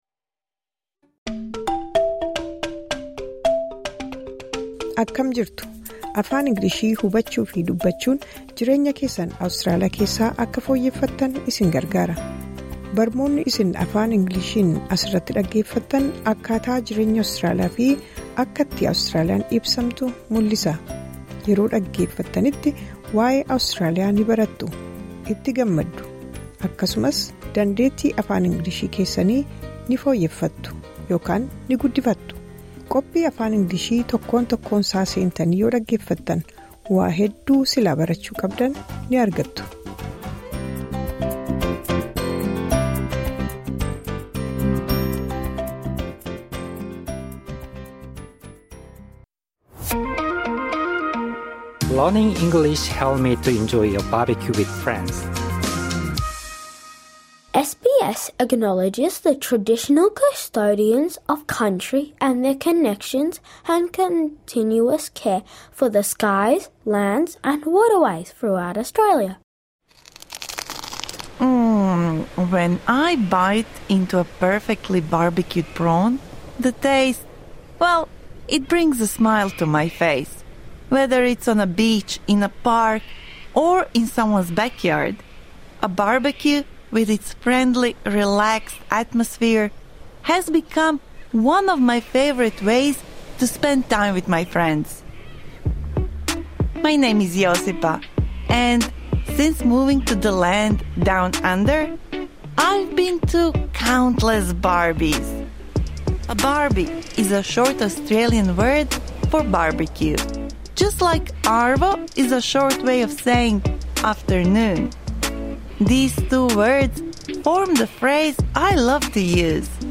This lesson is suitable for intermediate-level learners.
See if you can hear any more of these shortenings and slang words while you are out and about.